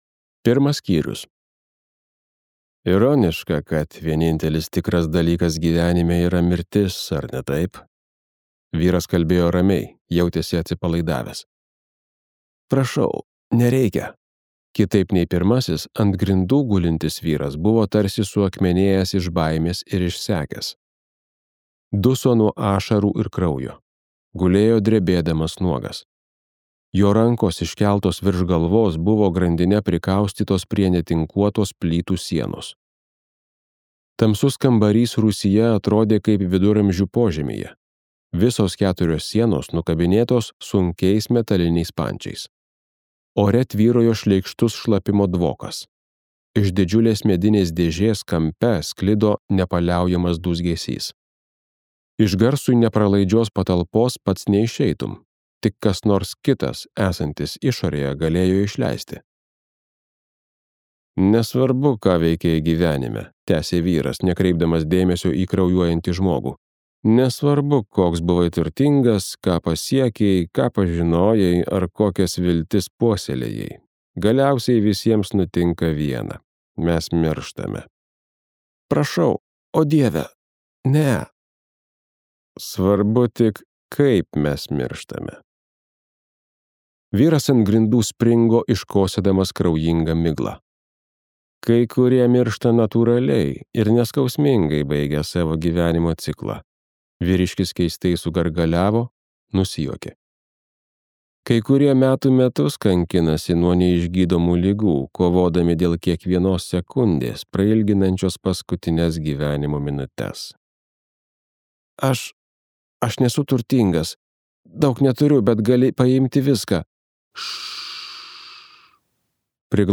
Egzekutorius | Audioknygos | baltos lankos